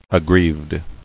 [ag·grieved]